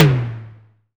Tuned drums (C# key) Free sound effects and audio clips
• Ambient Tome Drum Sample C# Key 67.wav
Royality free tom sample tuned to the C# note. Loudest frequency: 955Hz
ambient-tome-drum-sample-c-sharp-key-67-RHd.wav